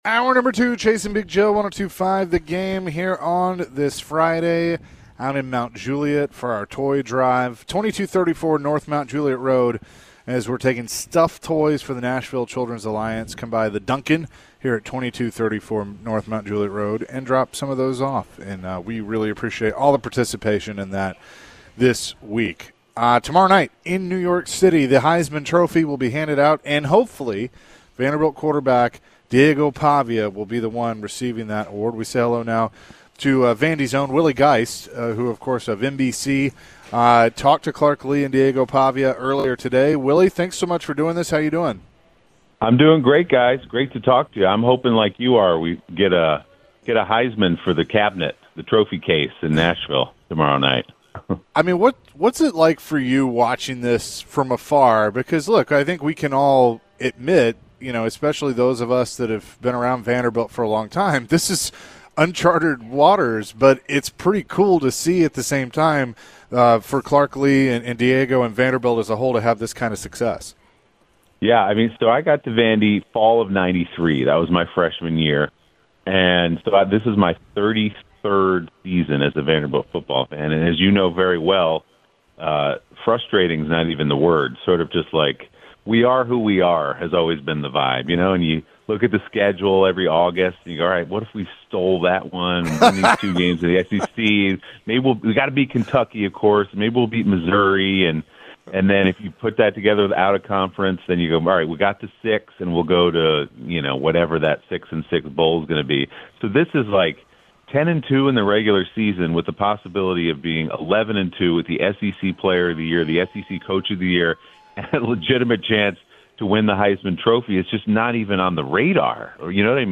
The guys speak with NBC host Willie Geist about Vanderbilt Football, Diego Pavia, Clark Lea, and Willie's story about how he ended up attending Vanderbilt for college.